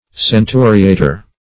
Search Result for " centuriator" : The Collaborative International Dictionary of English v.0.48: Centuriator \Cen*tu"ri*a`tor\, Centurist \Cen"tu*rist\, n. [Cf. F. centuriateur.] An historian who distinguishes time by centuries, esp. one of those who wrote the "Magdeburg Centuries."